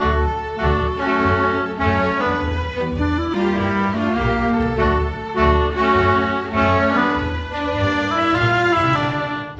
Rock-Pop 10 Orchestra 01.wav